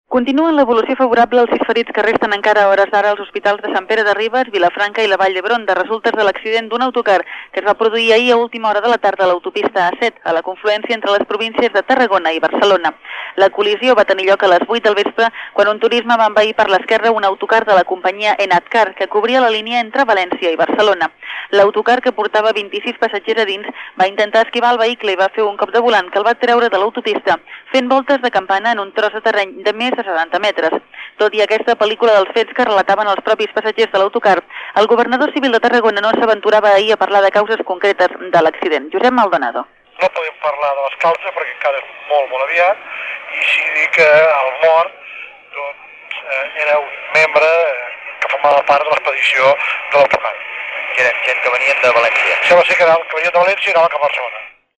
Informació telefònica sobre l'accident el dia anterior d'un autocar Enatcar. Una persona va resultar morta i setze ferides en bolcar un autocar a l'autopista A-7, entre Santa Margarida i els Monjos i Castellet i el Gornal
Informatiu